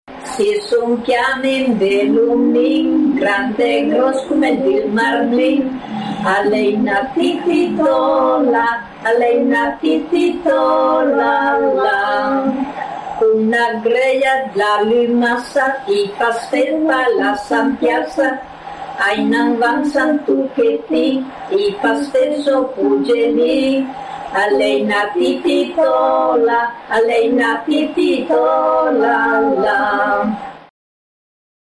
Il maritino / [registrata a Mondrezza, Viù (TO)